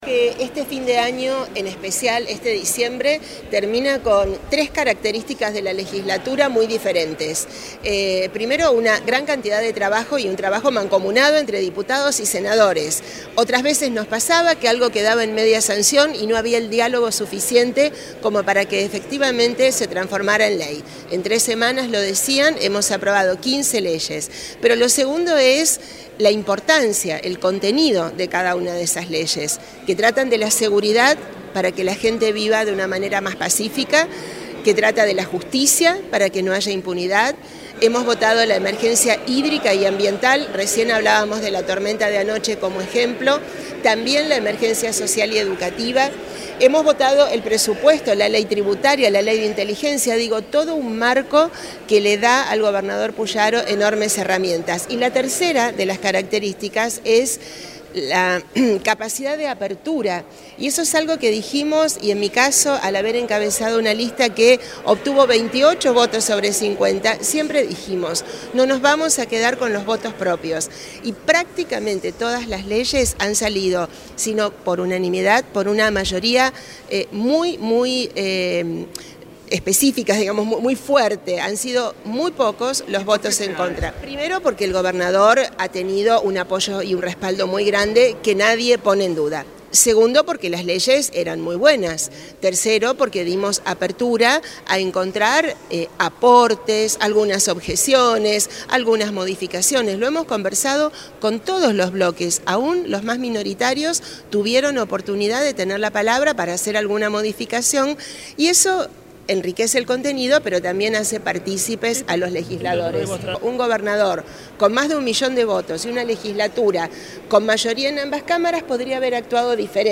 Escuchá la palabra de Clara García: